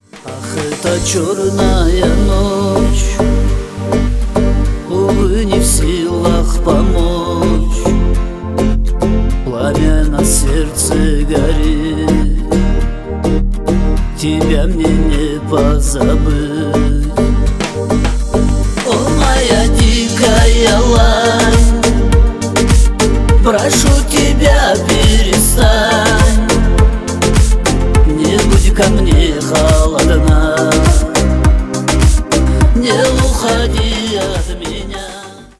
• Качество: 320 kbps, Stereo
Шансон
грустные